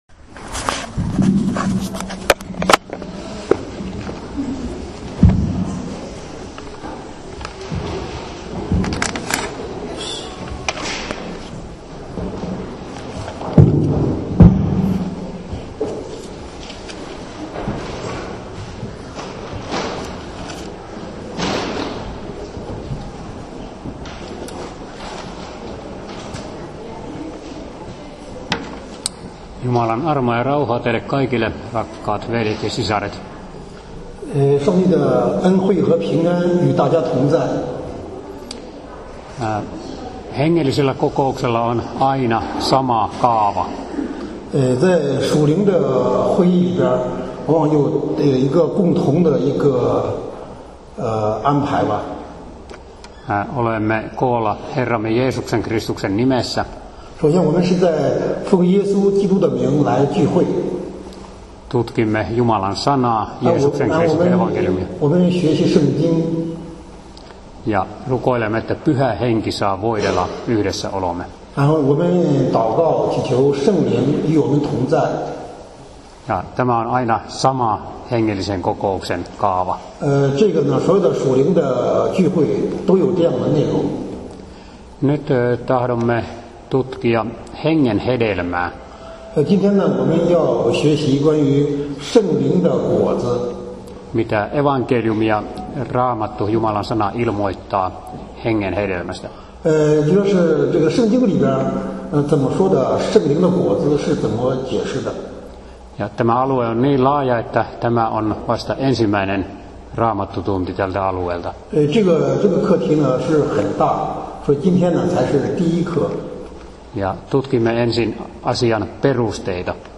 主日分享音频